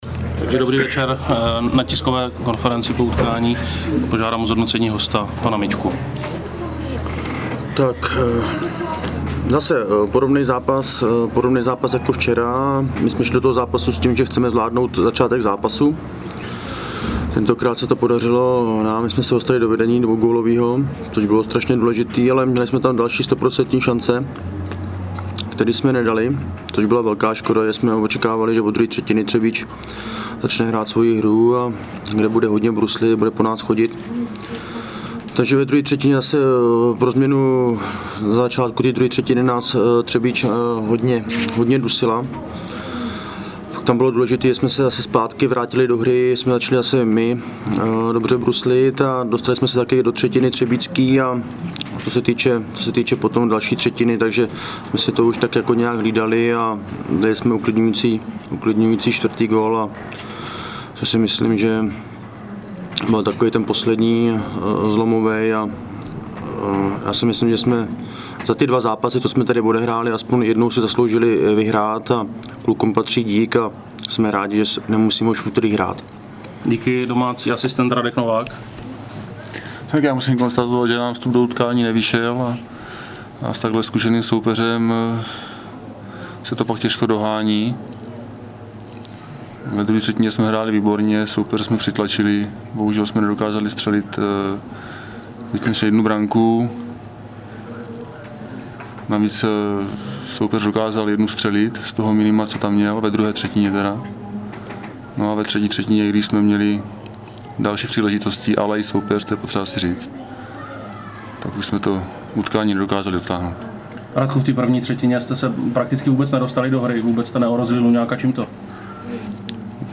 Tiskovka po utkání Třebíč - Hradec Králové 2:4
Tiskovka_po_utkani_T_584.MP3